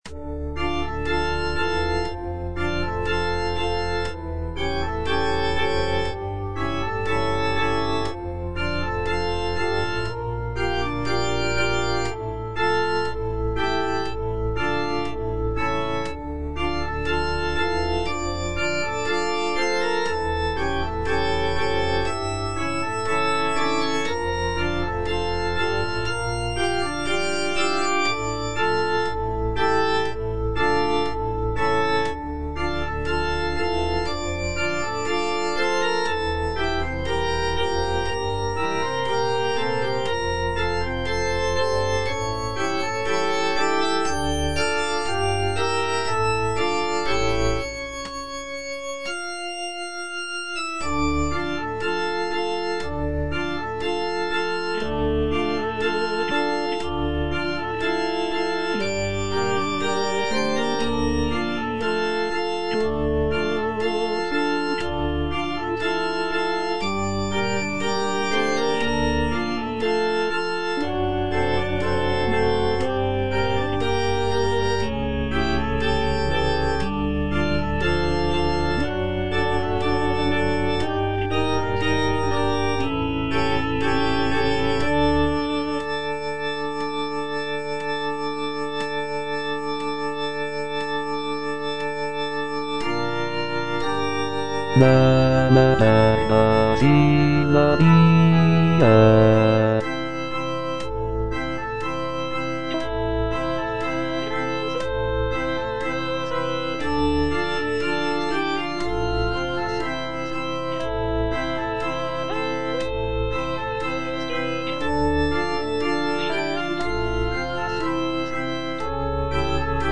F. VON SUPPÈ - MISSA PRO DEFUNCTIS/REQUIEM Recordare (bass II) (Voice with metronome) Ads stop: auto-stop